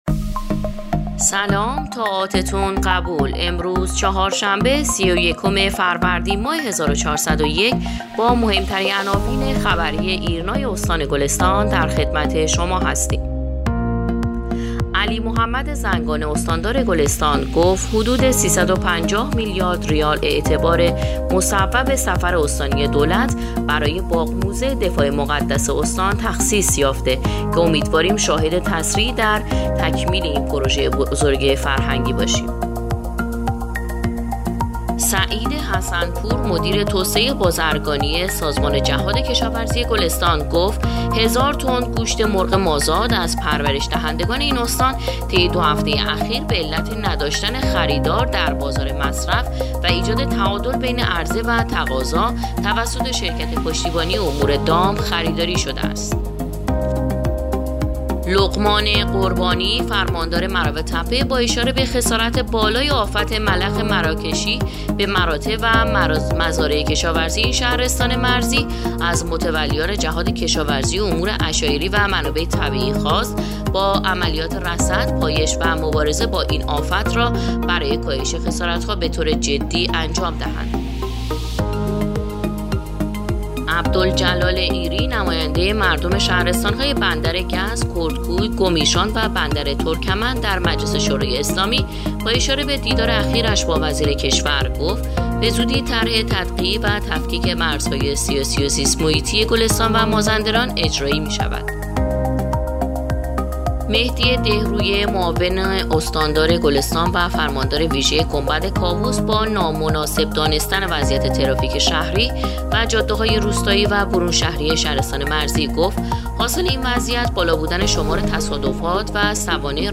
پادکست/ اخبار شبانگاهی سی و یکم فروردین ماه ایرنا گلستان